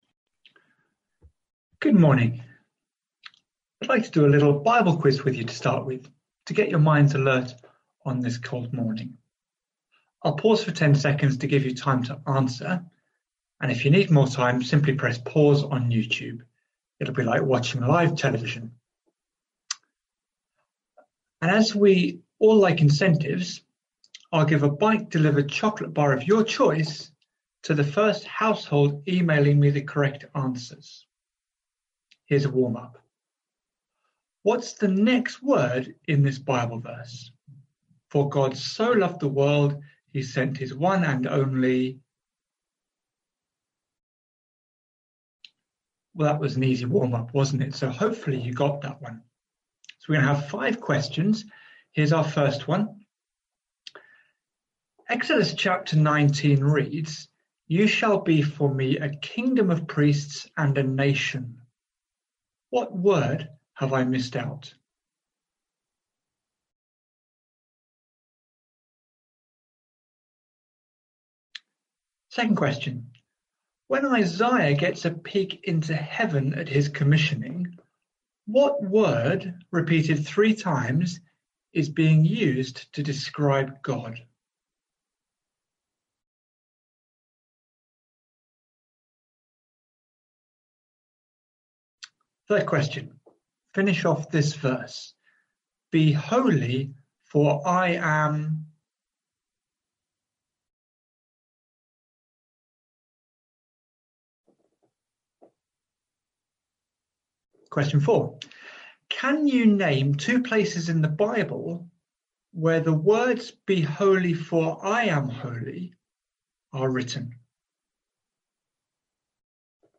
Single Sermon | Hope Church Goldington
Sunday Worship Together 7th March Sermon.mp3